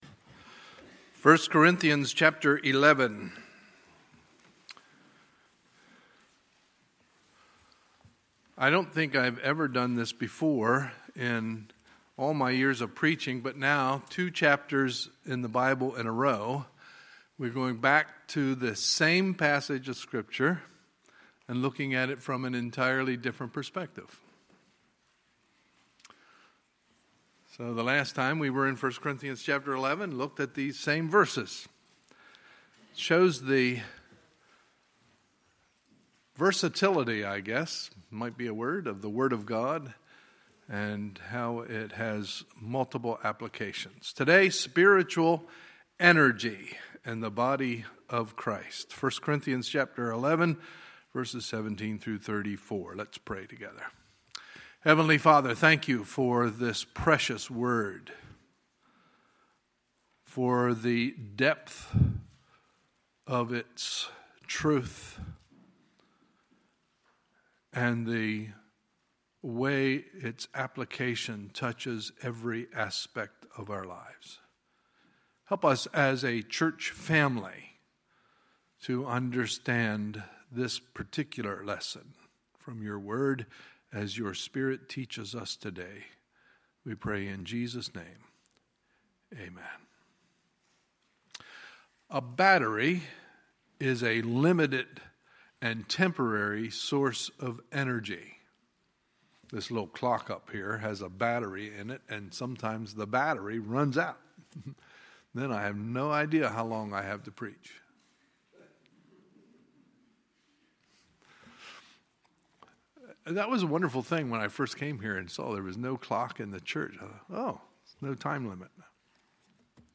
Sunday, May 7, 2017 – Sunday Morning Service